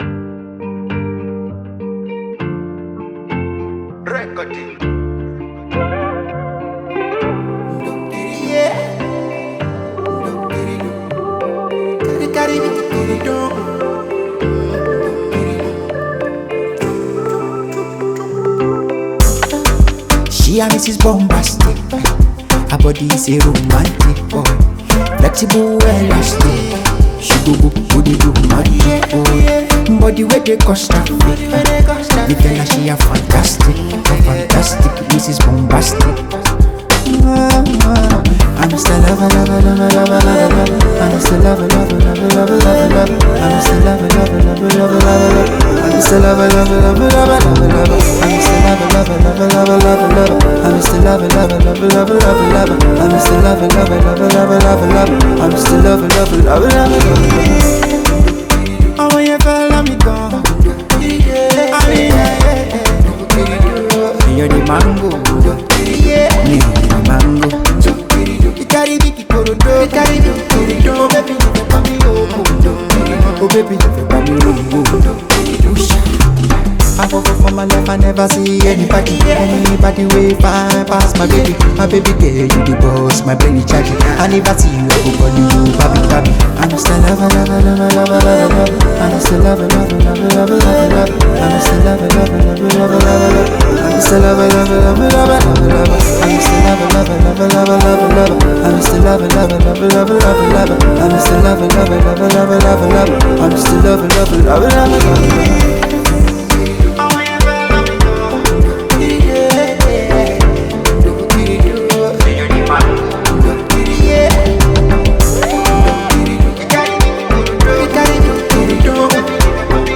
Talented Nigerian singer and songwriter